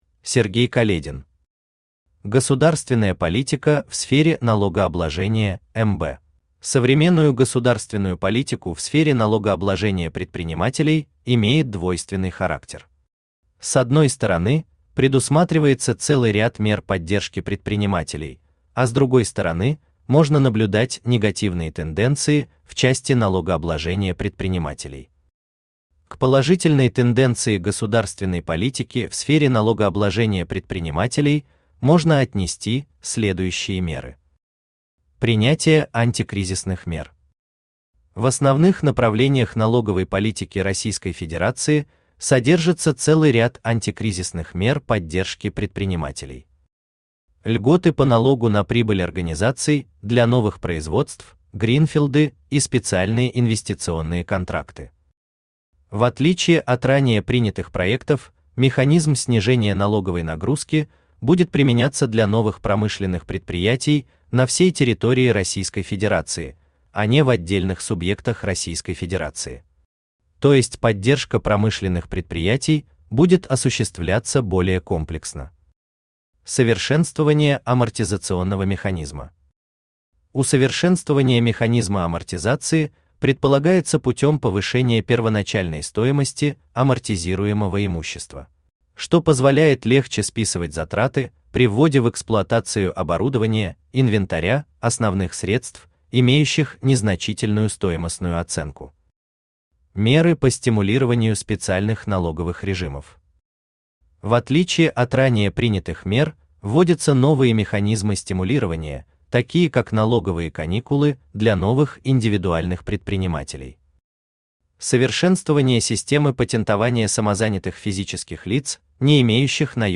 Аудиокнига Государственная политика в сфере налогообложения МБ | Библиотека аудиокниг
Aудиокнига Государственная политика в сфере налогообложения МБ Автор Сергей Каледин Читает аудиокнигу Авточтец ЛитРес.